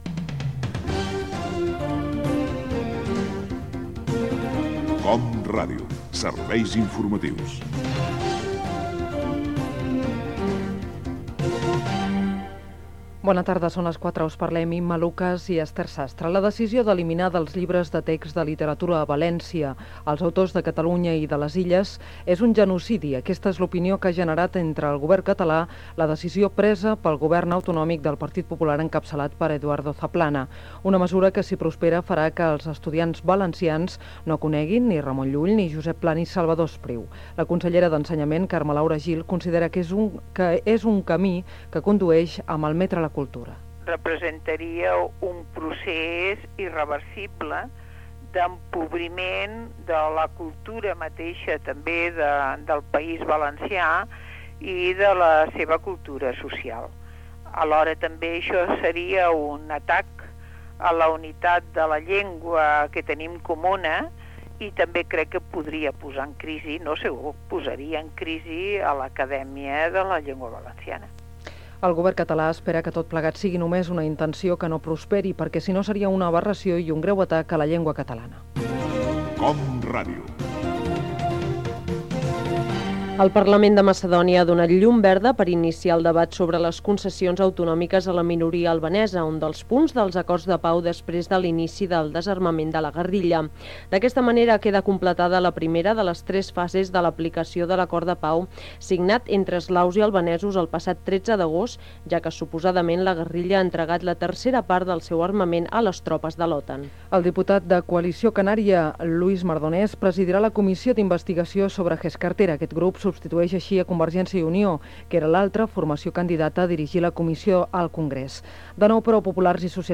Butlletí de les 16.00 hores. Careta del programa. El govern popular valencià vol eliminar els autors catalans dels llibres de text de l'ensenyament valencià, parlament de Macedònia, Jesús Gil, talls de llum a la Costa Brava, grup Freixenet, esports.
Informatiu